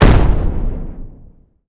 explosion_large_01_n.wav